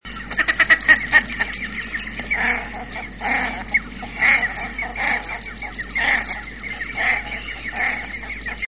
Ohar - Tadorna tadorna
głosy